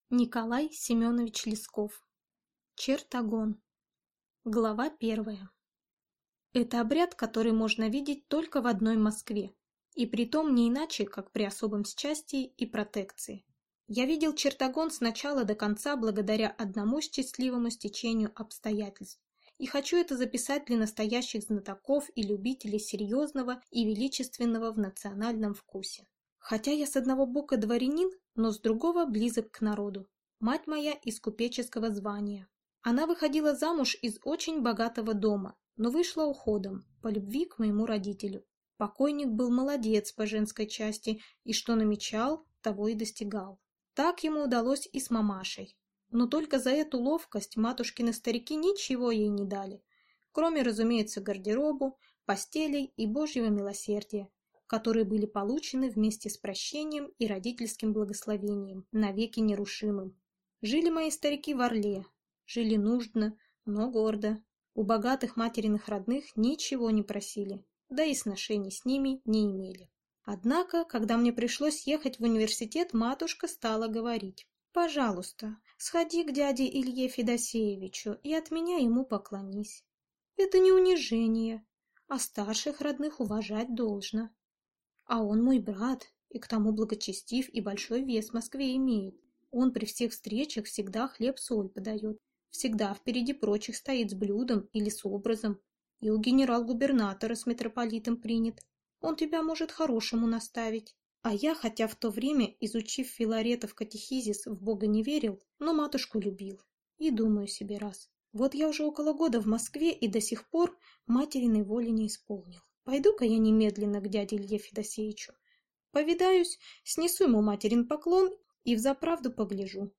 Аудиокнига Чертогон | Библиотека аудиокниг